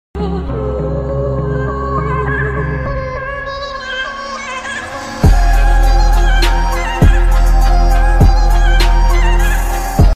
Aseel hen defeated aseel roster